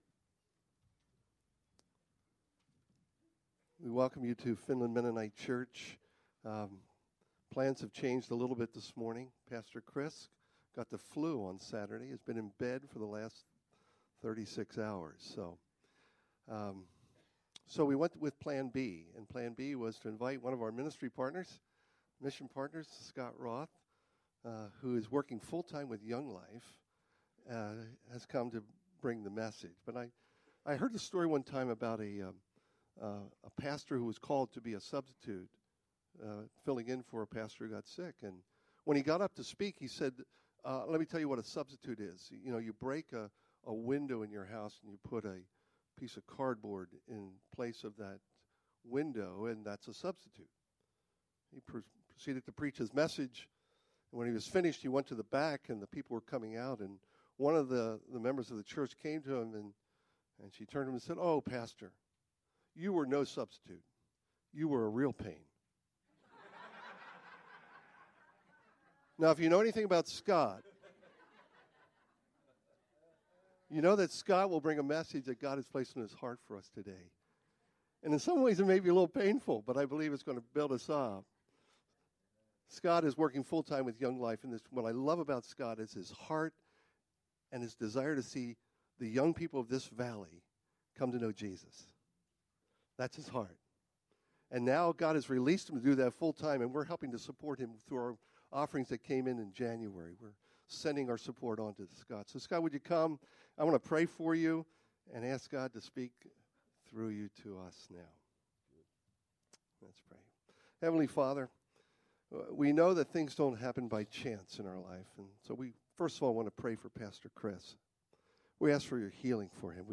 Genuine Prayer Sermon Series